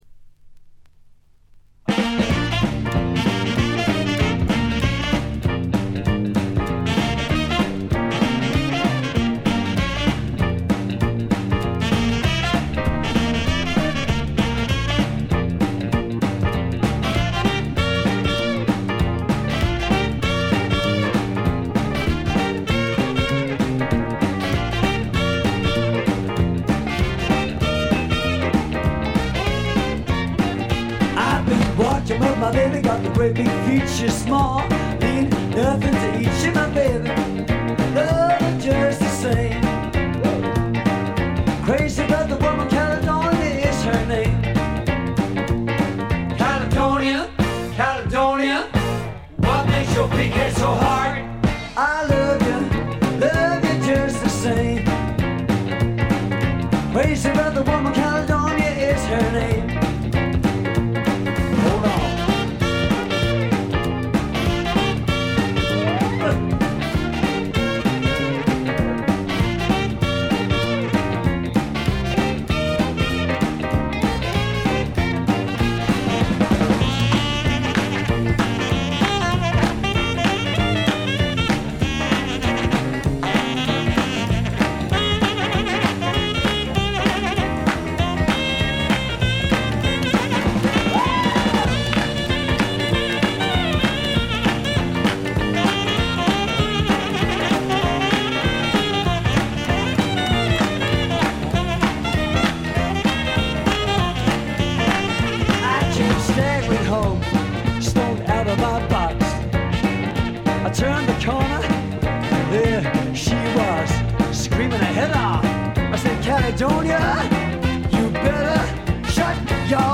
ほとんどノイズ感無し。
聴くたびにご機嫌なロックンロールに身をゆだねる幸せをつくずく感じてしまいますね。
試聴曲は現品からの取り込み音源です。